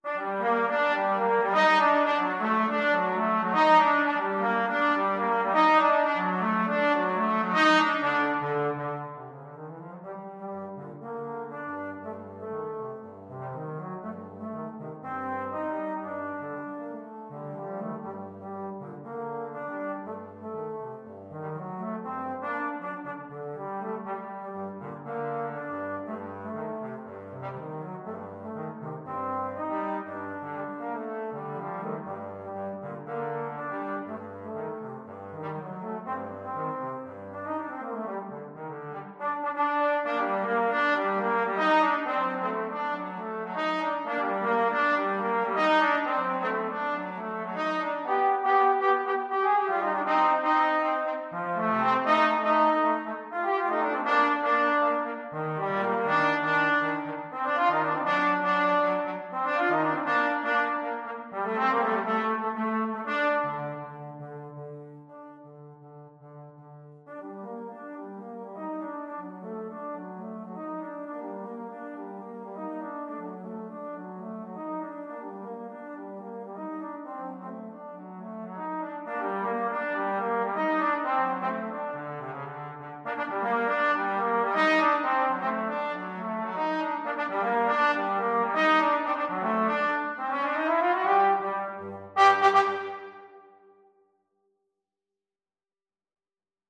A firey salsa-inspired piece.
Energico =120
4/4 (View more 4/4 Music)
Jazz (View more Jazz Trombone Duet Music)